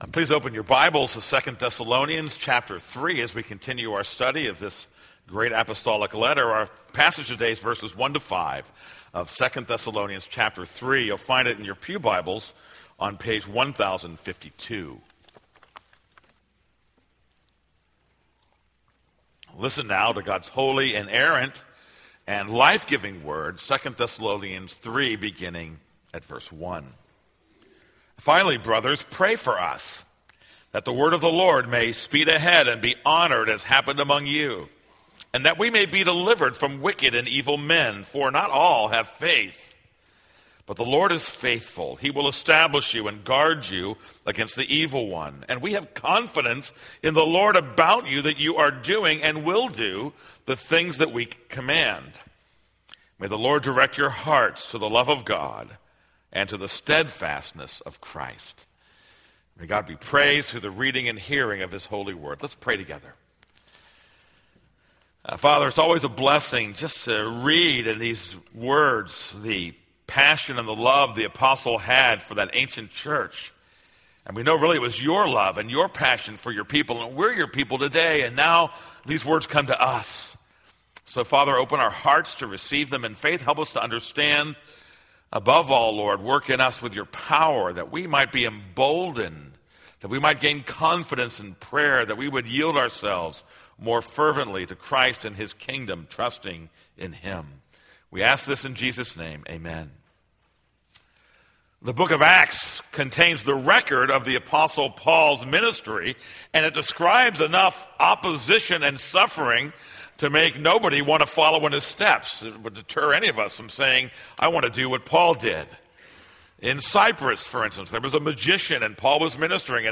This is a sermon on 2 Thessalonians 3:1-5.